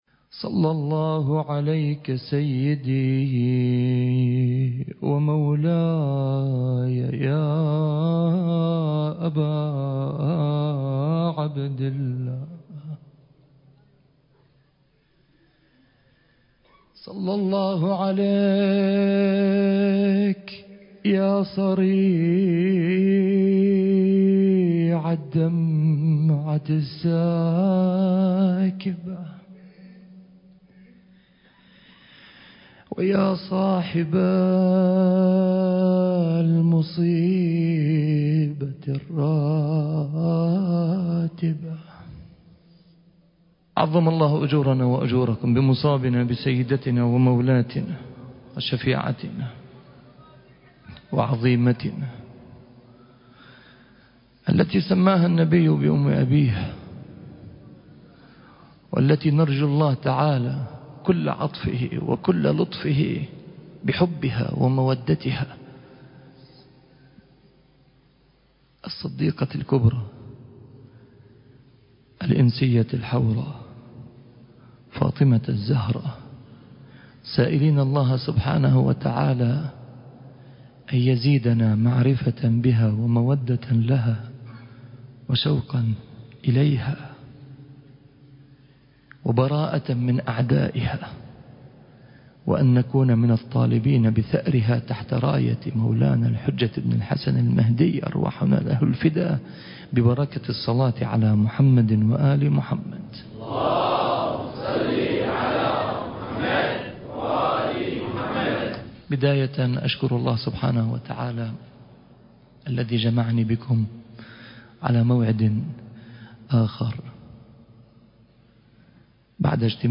المكان: هيئة شباب المنتظر - بغداد